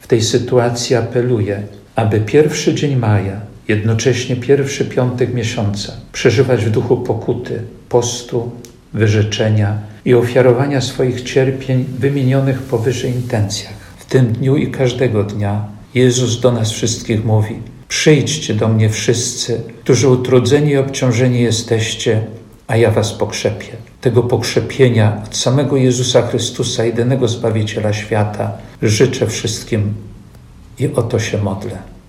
Chociaż początek maja kojarzy się z długim weekendem, ze względu na epidemię tegoroczna majówka będzie inna – dodaje biskup ełcki.